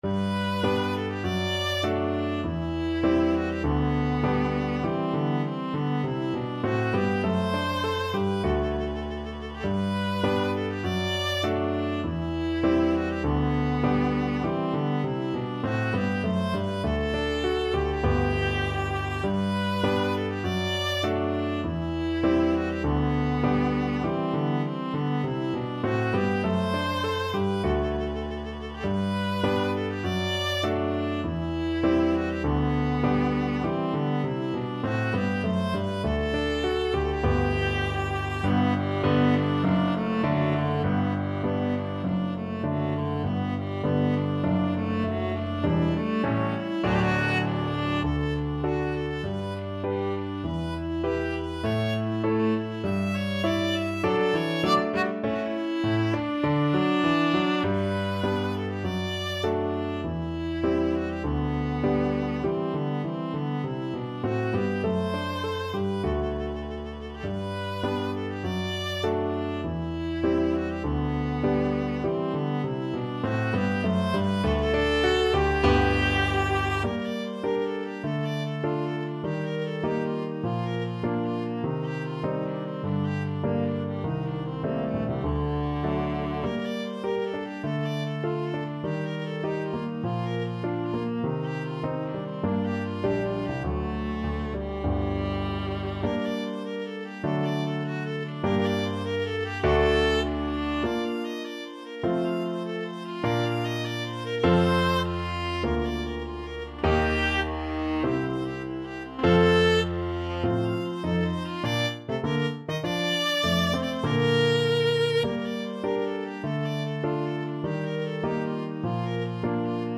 Classical Rameau, Jean-Philippe Gavotte from the opera Le temple de la Gloire Viola version
Viola
~ = 100 Allegretto
G major (Sounding Pitch) (View more G major Music for Viola )
2/2 (View more 2/2 Music)
Classical (View more Classical Viola Music)